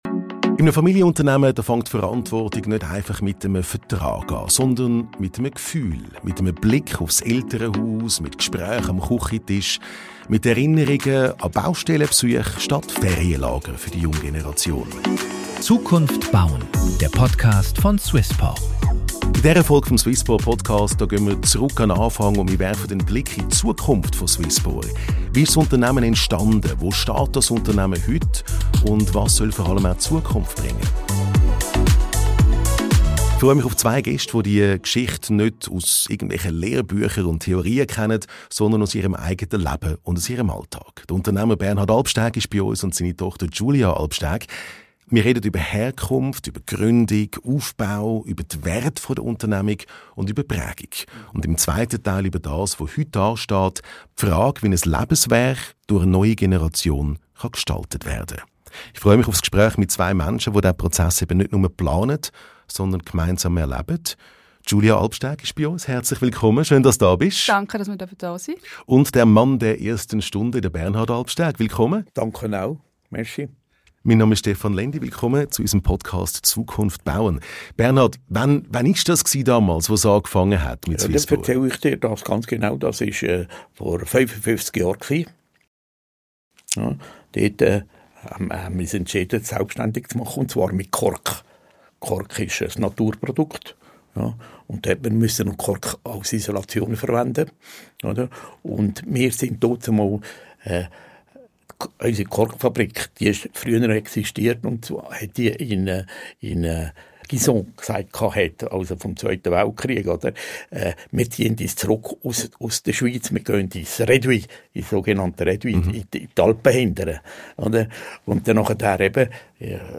Und um die Frage, wie man loslässt, ohne den Halt zu verlieren. Ein ehrliches, persönliches Gespräch über Herkunft, Wandel und den Mut, Verantwortung weiterzugeben.